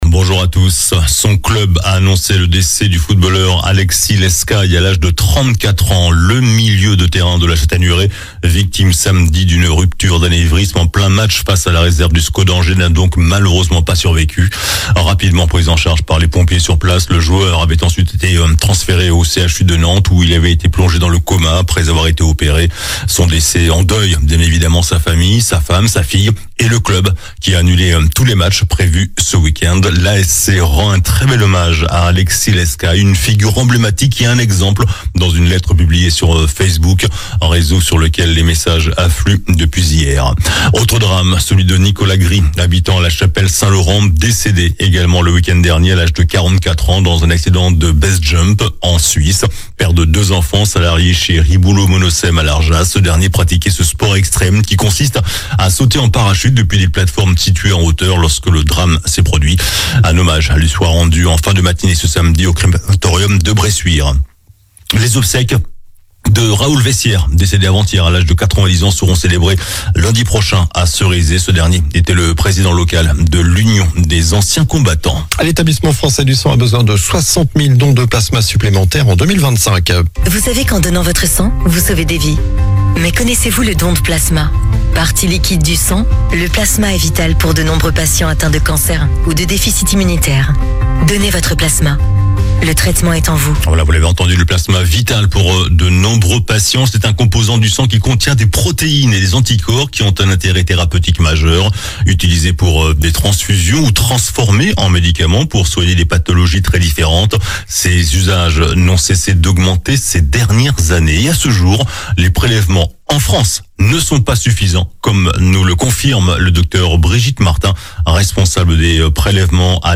JOURNAL DU SAMEDI 12 OCTOBRE